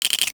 NOTIFICATION_Rattle_06_mono.wav